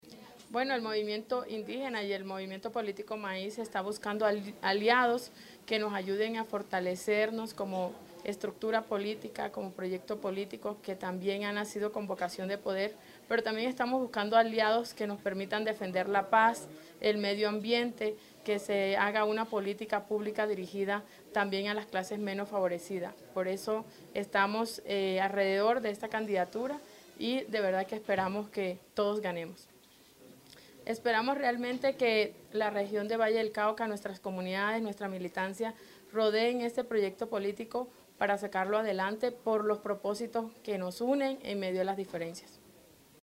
Declaraciones Martha Isabel Peralta – presidenta del MAIS